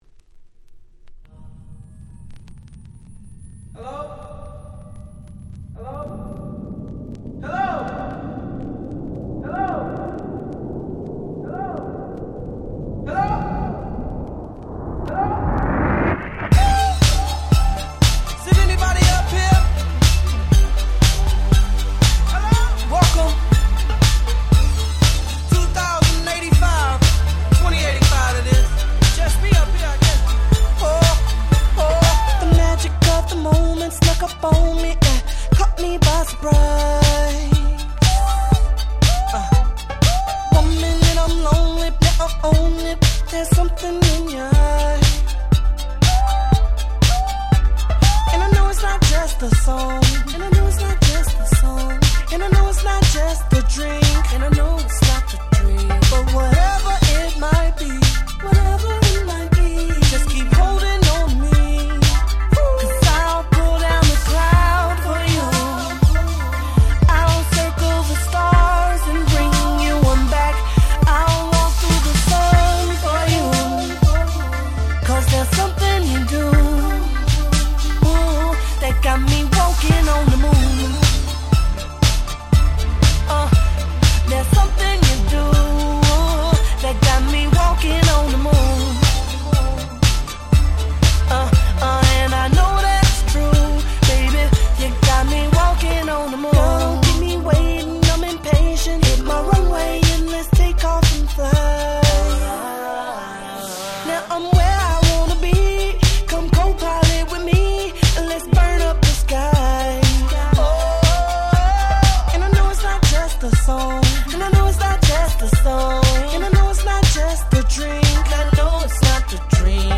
09' Smash Hit R&B !!